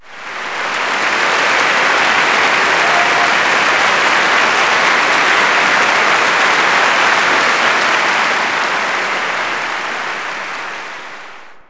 clap_042.wav